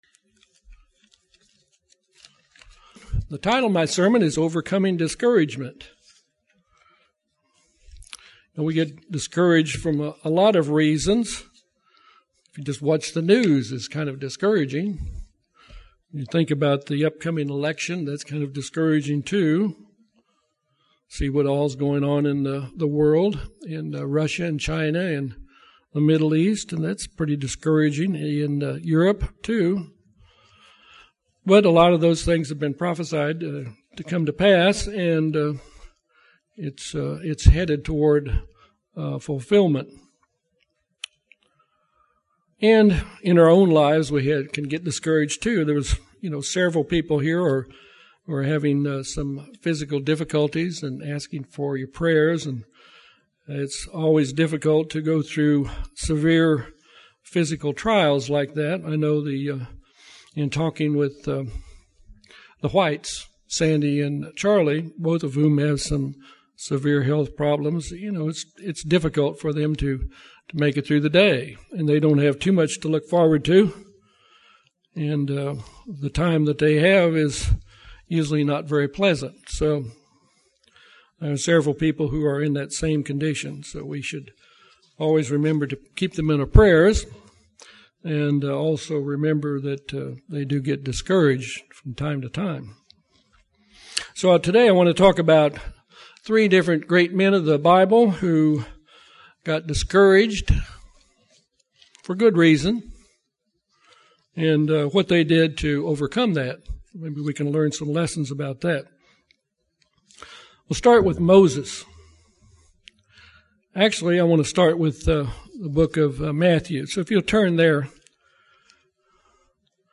This sermon looks at lessons from great men of God who overcame discouragement